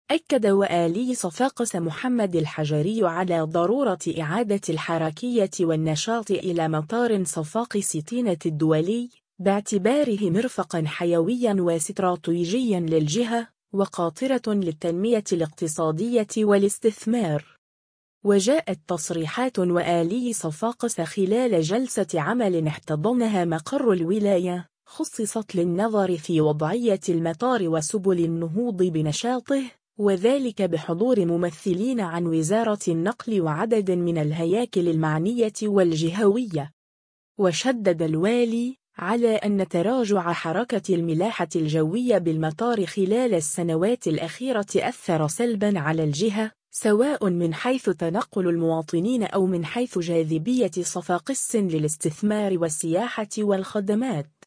وجاءت تصريحات والي صفاقس خلال جلسة عمل احتضنها مقر الولاية، خُصّصت للنظر في وضعية المطار وسبل النهوض بنشاطه، وذلك بحضور ممثلين عن وزارة النقل وعدد من الهياكل المعنية والجهوية.